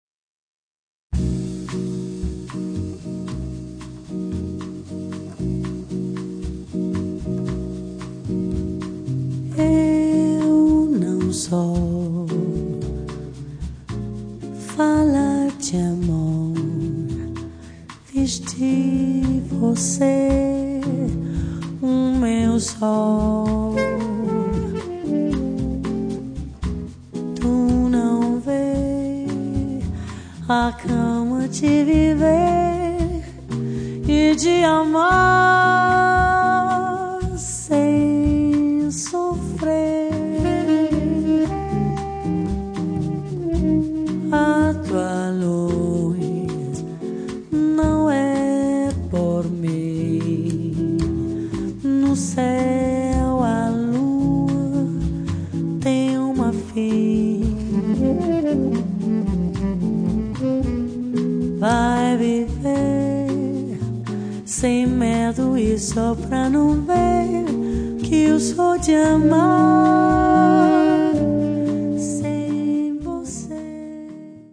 vocal, arrangements
Saxophone
guitar
double bass
drums
Brani raffinati, di corposa eleganza.